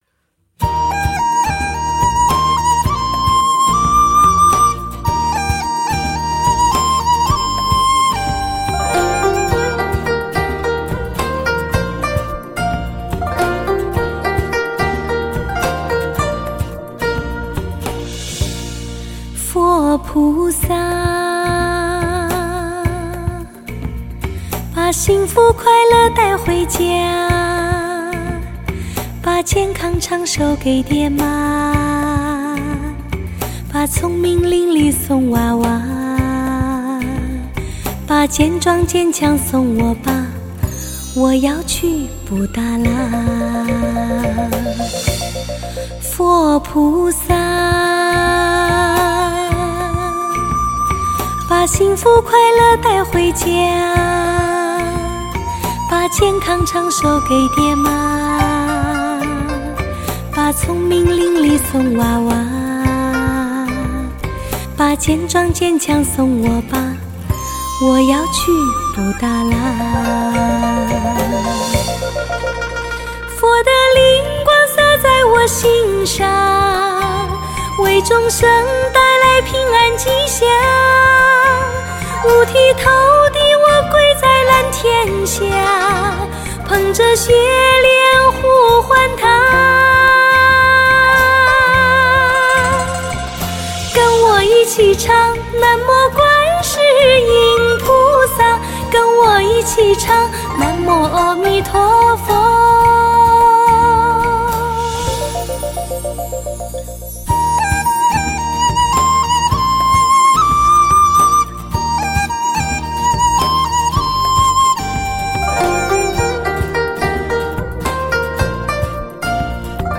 她的嗓音清纯、通透、平实而不苍白，细腻而不做作，歌声清澈透明，撩人心弦，